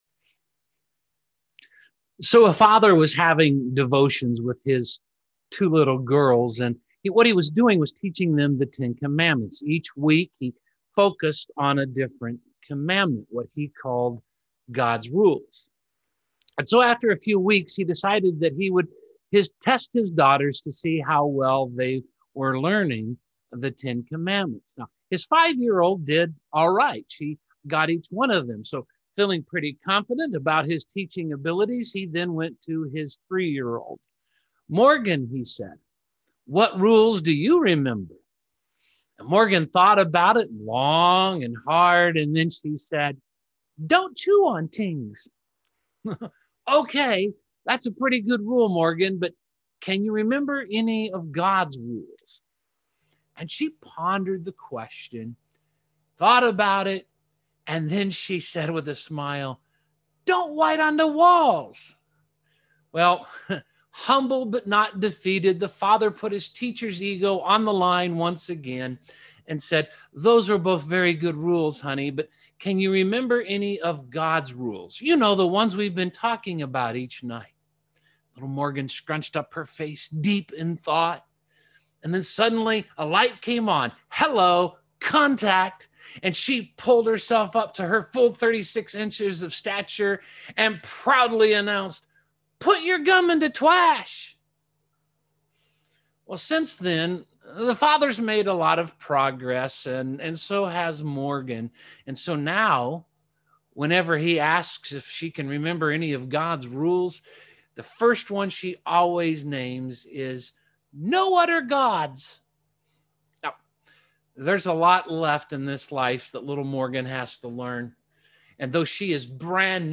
Ten Commandments Exodus Ten Commandments Video Sermon Audio Sermon Save Audio Save PDF The 10 Commandments begin with putting God first.